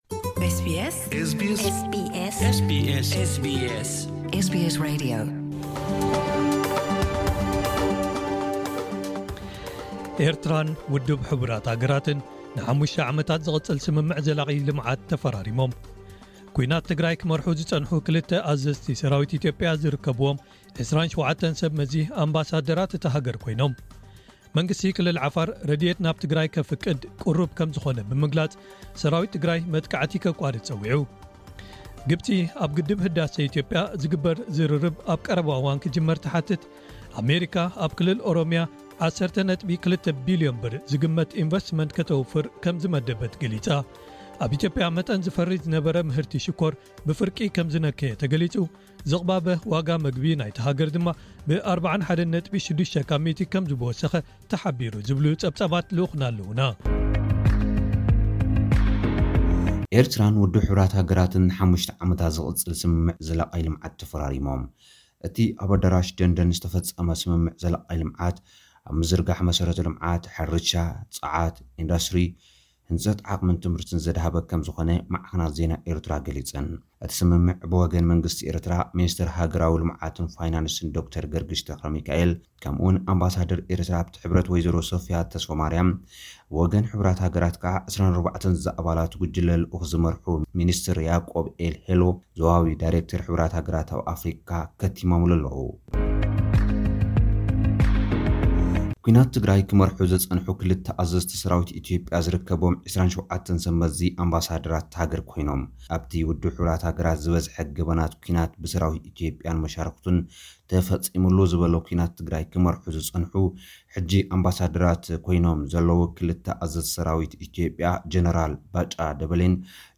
ሓጸርቲ ጸብጻባት ዜና፡ * ኤርትራን ው. ሕ. ሃ.ን ንሓሙሽተ ዓመታት ዝቕጽል ስምምዕ ዘላቒ ልምዓት ተፈራሪሞም፡ * ኲናት ትግራይ ክመርሑ ዝጸንሑ ክልተ ኣዘዝቲ ሰራዊት ኢትዮጵያ ዝርከብዎም 27 ሰብ መዚ ኣምባሳደራት’ታ ሃገር ኮይኖም፡